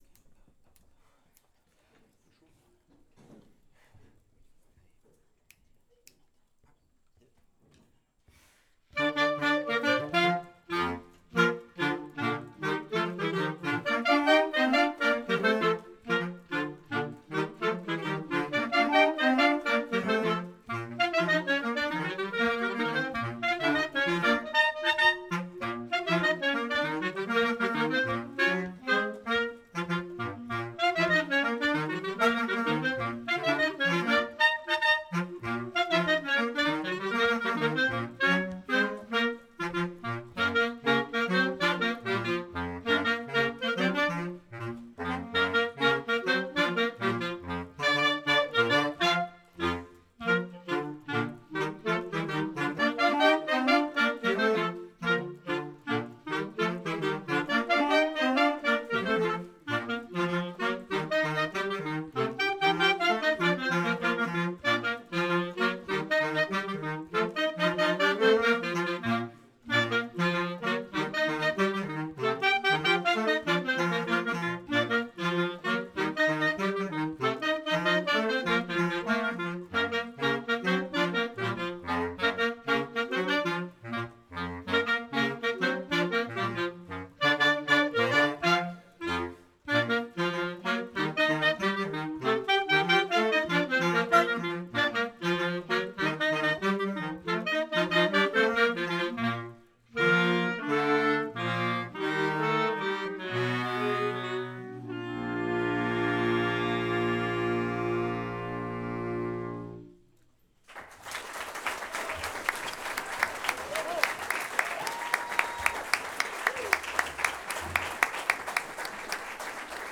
Cela donne notamment l’occasion à l’ensemble de clarinettes de présenter les morceaux que nous avons travaillés…